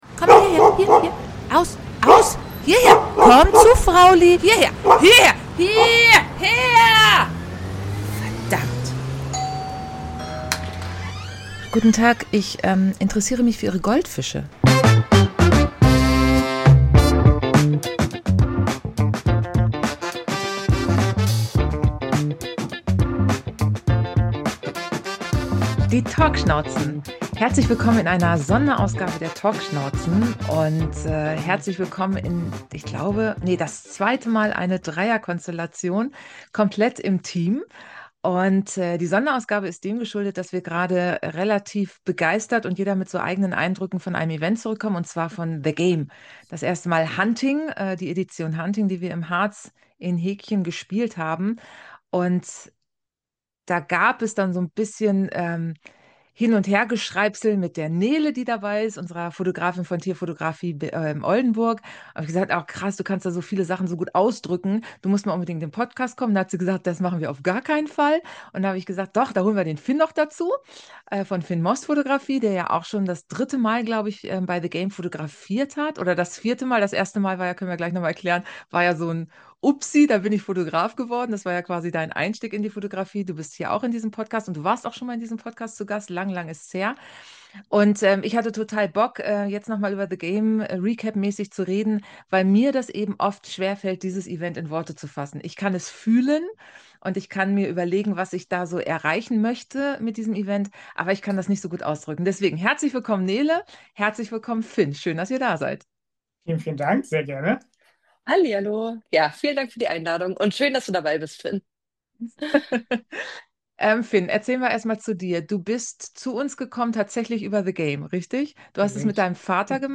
Beschreibung vor 10 Monaten In Dreierbesetzung hauen die Talkschnauzen diese Sonderfolge über das THE GAME Live Event 2025 raus.